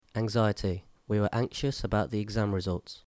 6 Anxiety æŋˈzaɪəti